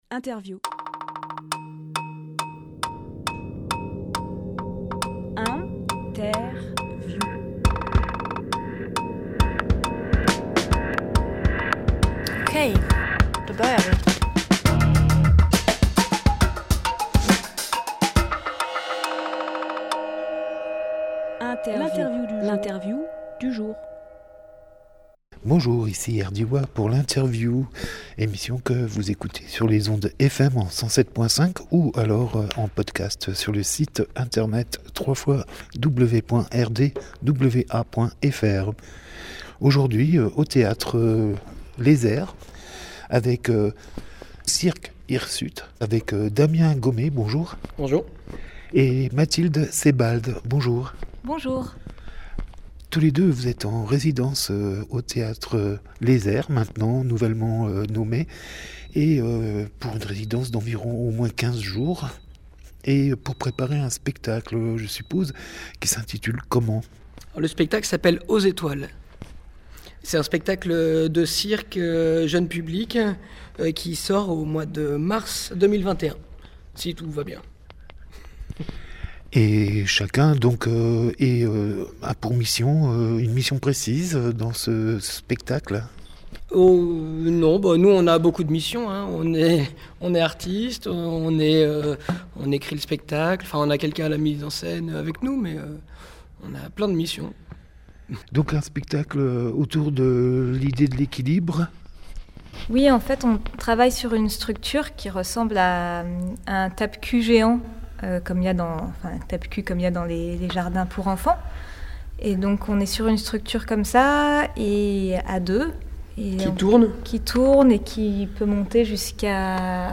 Emission - Interview Le Cirque hirsute aux étoiles Publié le 22 décembre 2020 Partager sur…
21.12.20 Lieu : studio RDWA Durée